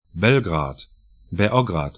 Pronunciation
Belgrad 'bɛlgra:t Beograd bɛ'ɔgrad sr Stadt / town 44°50'N, 20°30'E